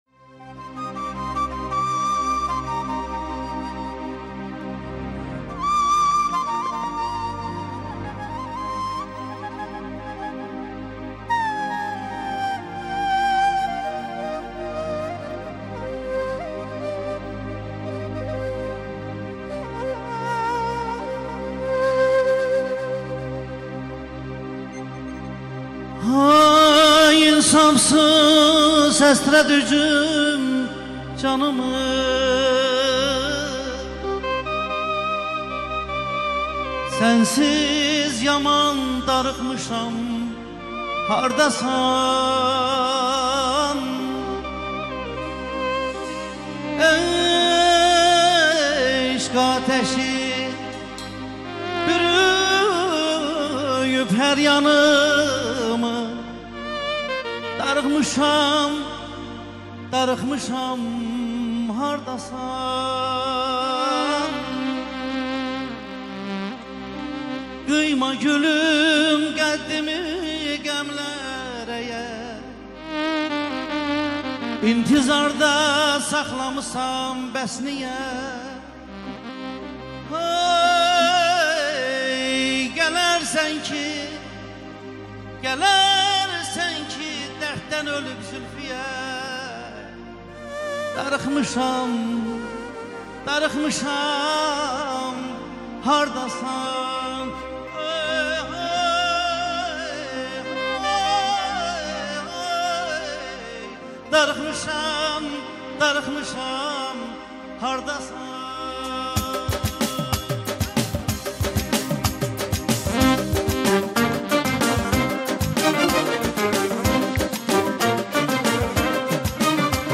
CANLI İFA